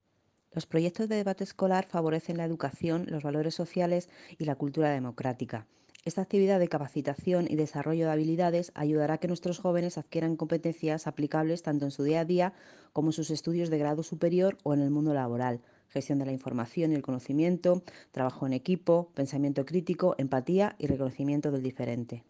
Virginia-Jiménez-concejala-de-Educación-2.mp3